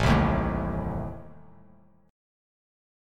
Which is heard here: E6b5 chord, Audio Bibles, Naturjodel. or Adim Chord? Adim Chord